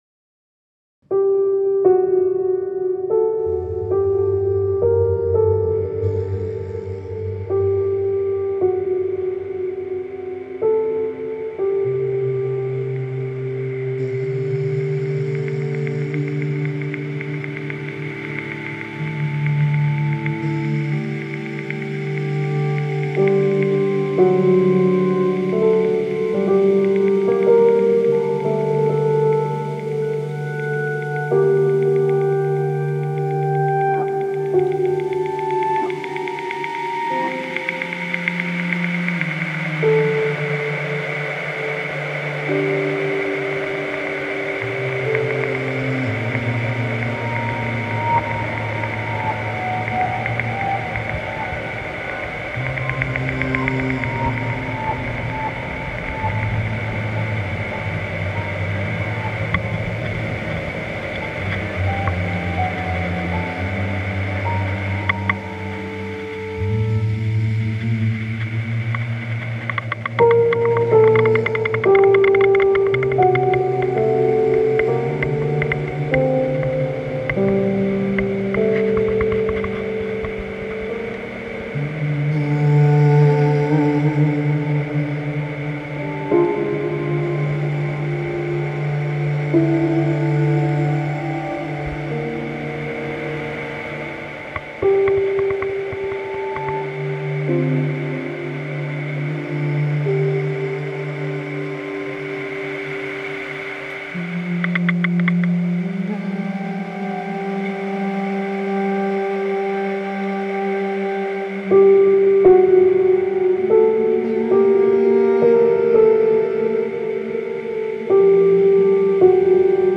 North Pole ice recording reimagined